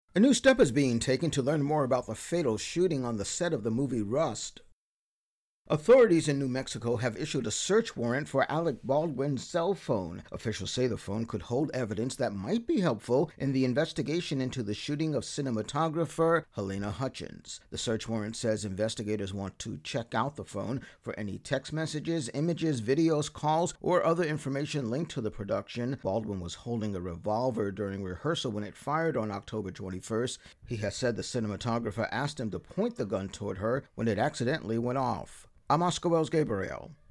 Intro+voicer for search warrant issued for Alec Baldwin's phone